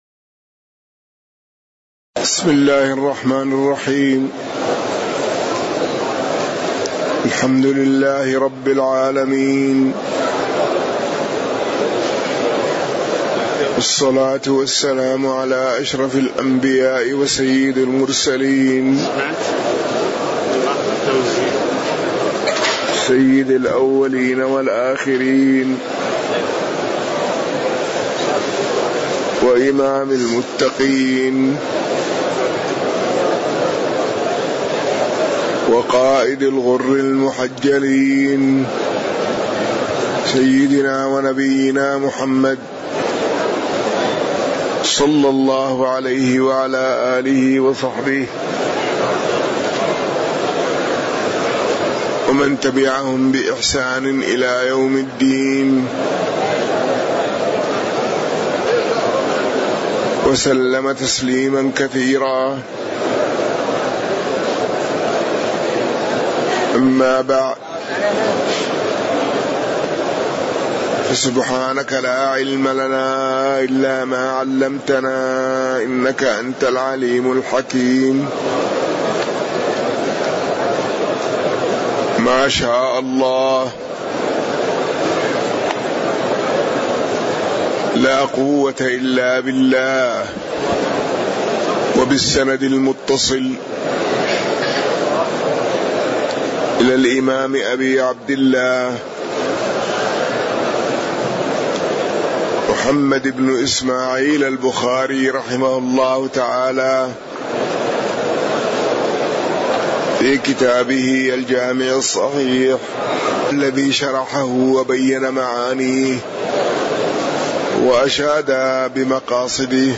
تاريخ النشر ٢ رمضان ١٤٤٠ هـ المكان: المسجد النبوي الشيخ